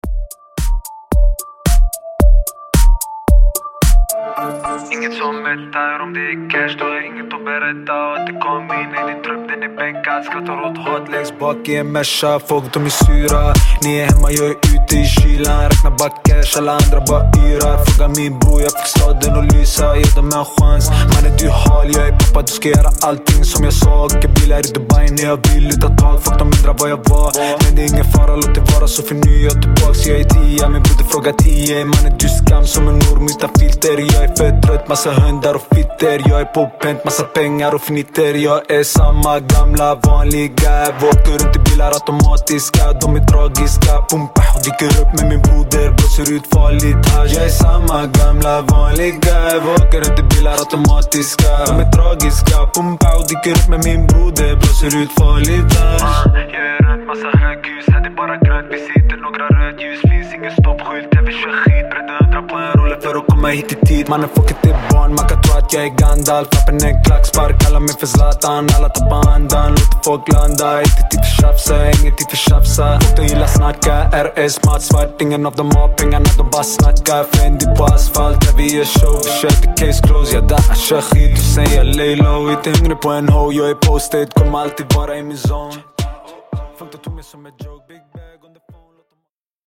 Genre: 90's
Clean BPM: 124 Time